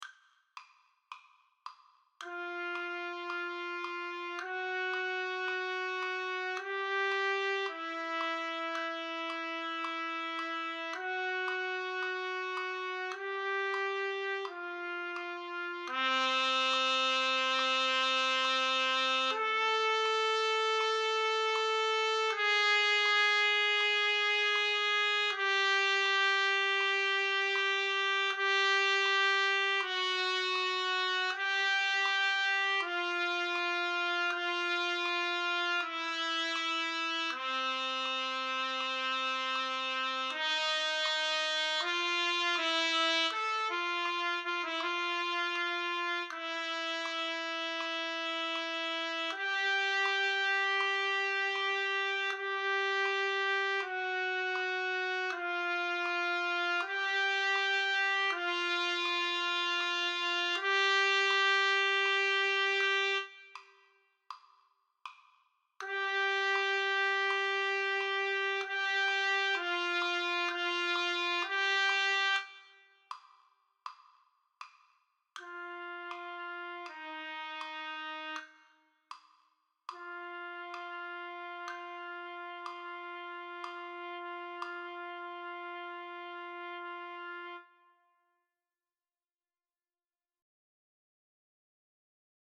Bari3
Silence on the files indicate a rest for your part.